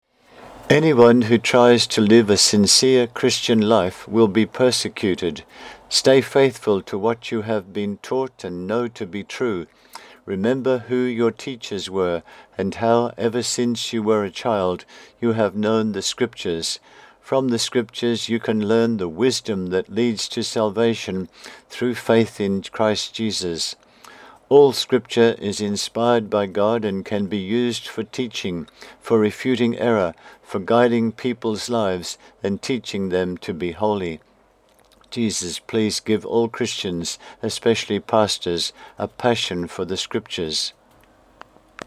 The Book Blog:   4 readings + recordings